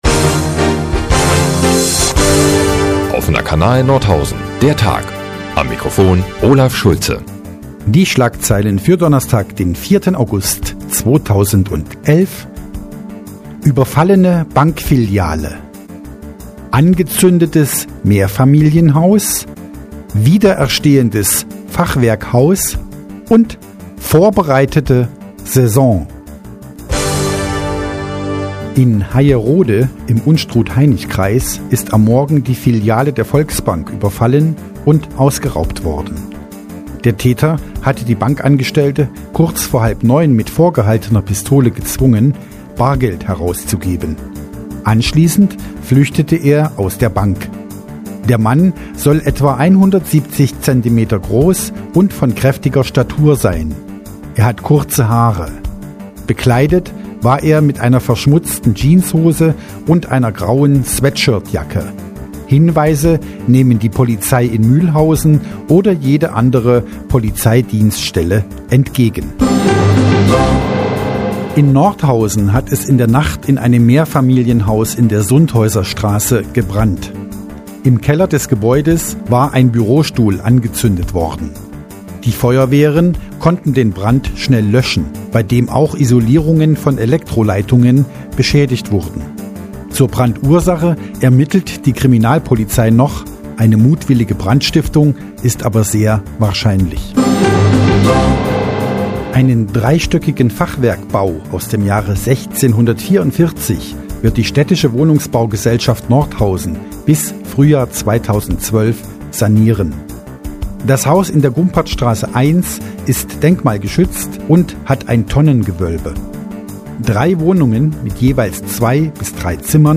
Die tägliche Nachrichtensendung des OKN ist nun auch in der nnz zu hören. Heute geht es um Banküberfall, Brandtsiftung, Fachwerksanierung und neue Fußballer.